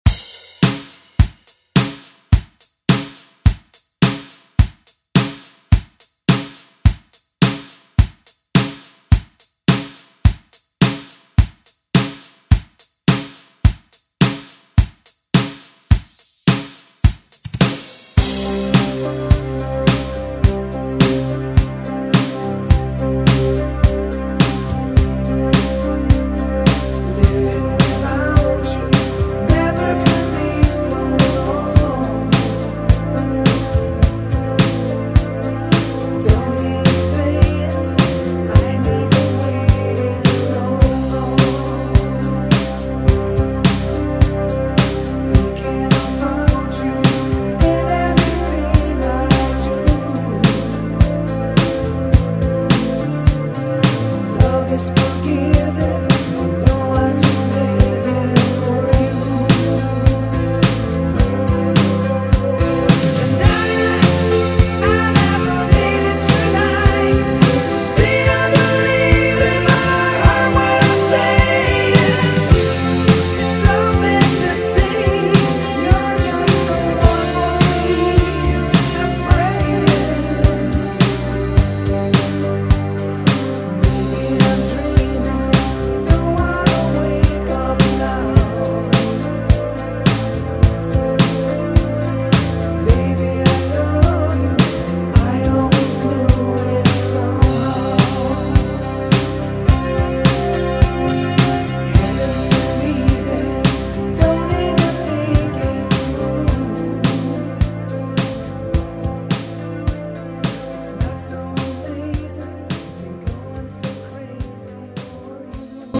Electronic Euro House Music
90's , DANCE , RE-DRUM Version: Clean BPM: 110 Time